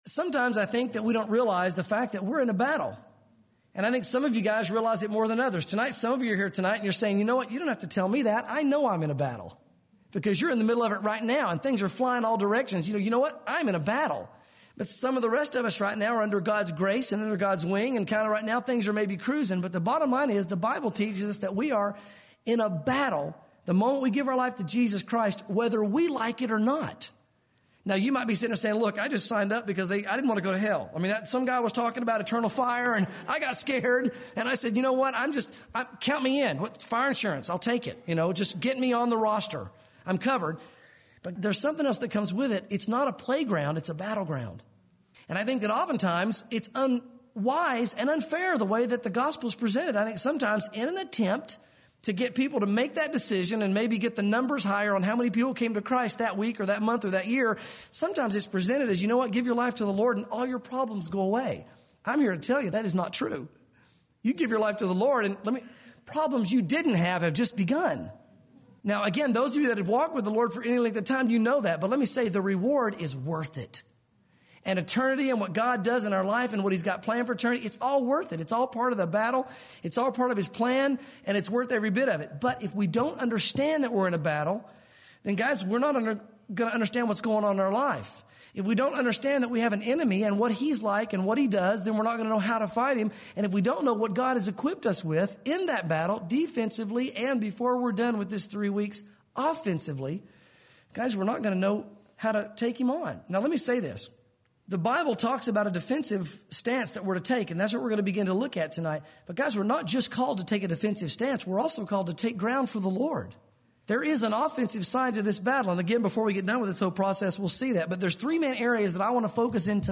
sermons Spiritual Warfare